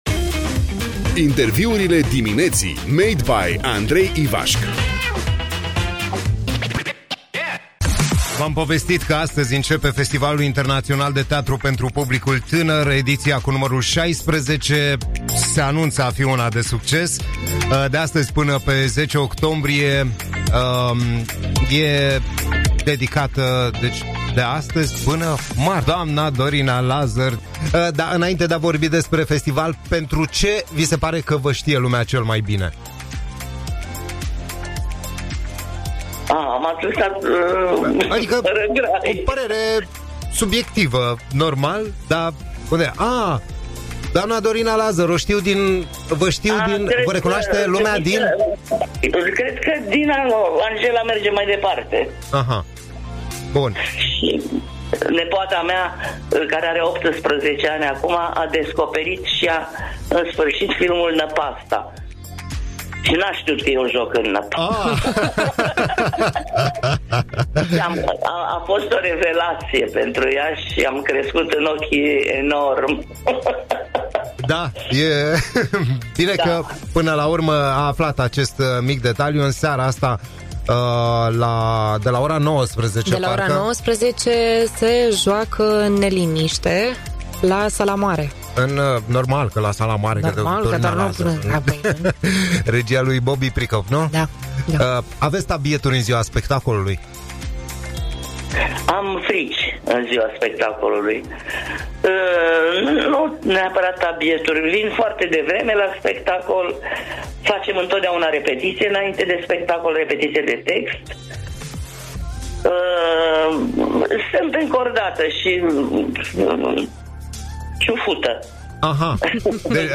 INTERVIU cu actrița Dorina Lazăr: Eu când nu mă uit în oglindă am 25 de ani. Regăsesc Iașul tot cu soare - Radio Hit